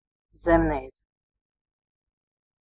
Ääntäminen
IPA : /ˈpɛzənt/ US : IPA : [pɛ.zənt]